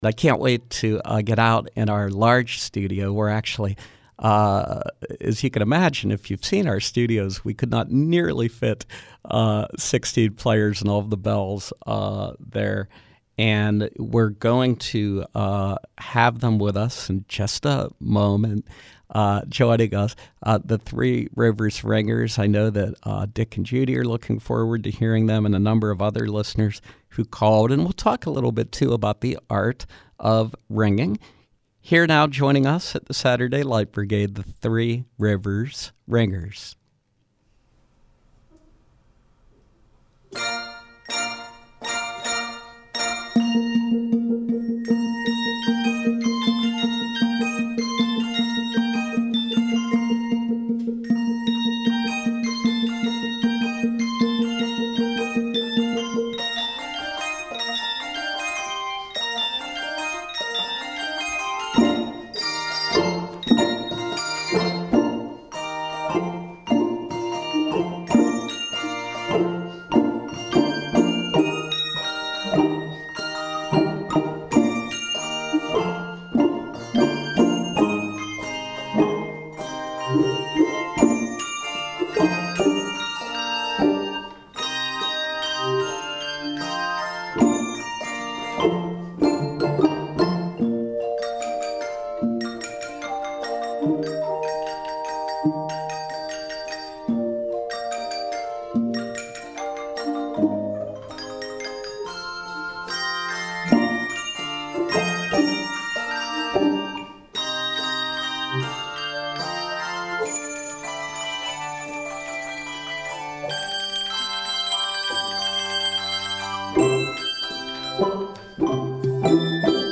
From 05/14/2016: Three Rivers Ringers preview film score selections for their Children’s Museum of Pittsburgh performances 5/14, 1 and 2 pm.